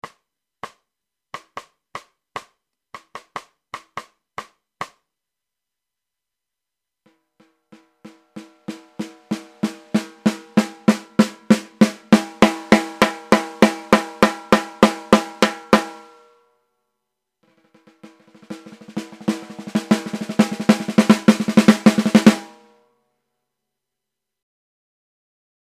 Auf Bitten in meinem Marschtrommelkabinett habe ich heute mal meine 5 Snaredrums aufgenommen.
C - Fibes Fiberglass 14x5
Am Teppichrascheln hätte ich sie auf den Aufnahmen noch auseinanderhalten können.